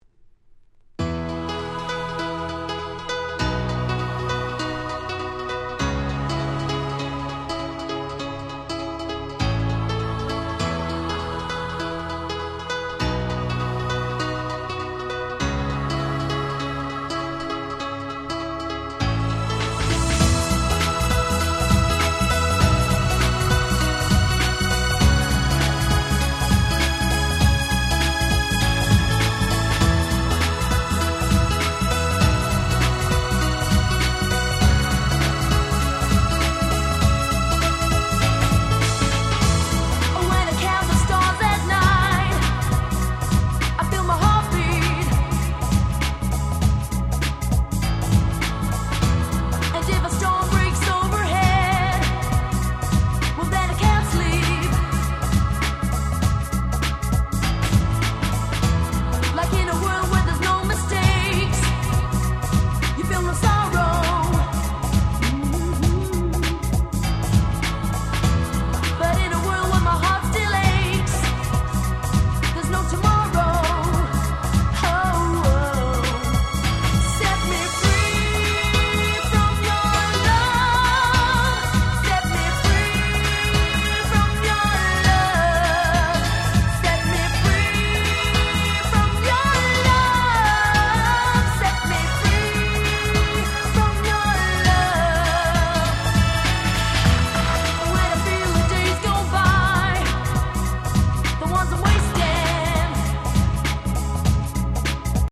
00' Nice Dance Pop / キャッチーR&B !!
レアで音質バッチリな国内プロモ！！